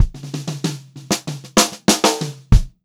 96POPFILL2-L.wav